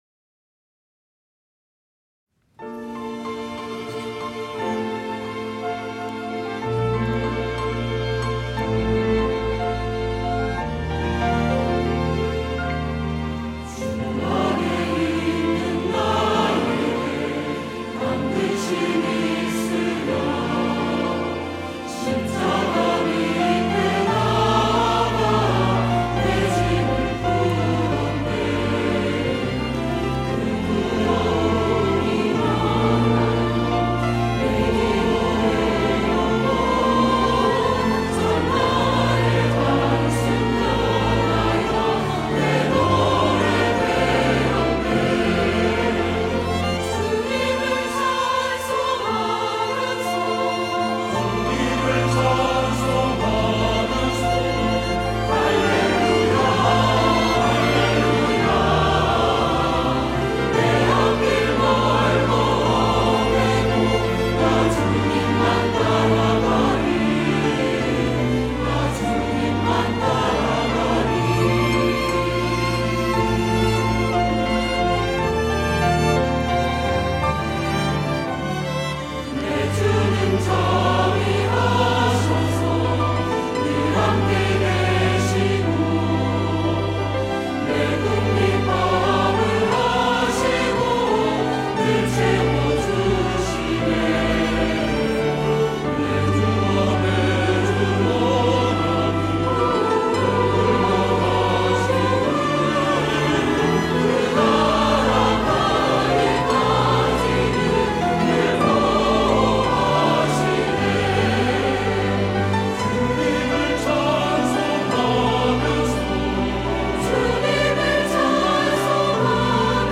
호산나(주일3부) - 주 안에 있는 나에게
찬양대